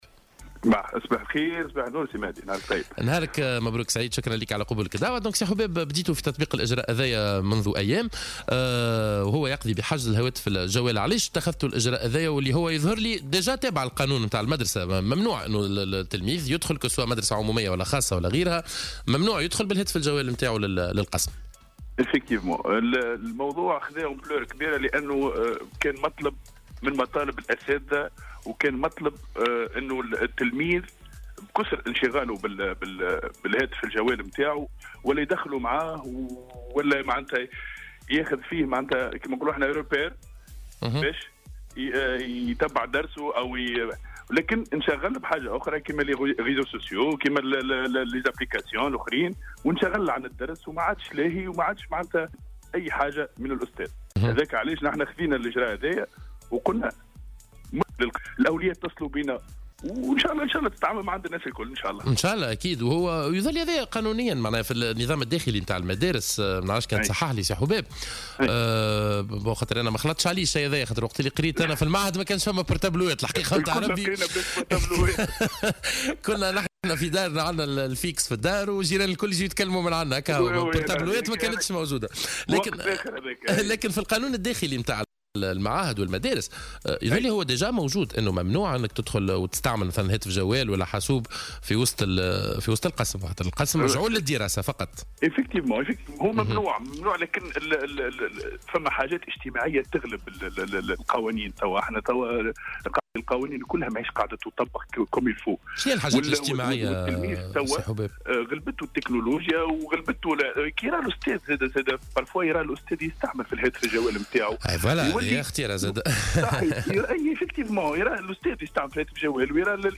في مداخلة له في صباح الورد على الجوهرة "أف أم"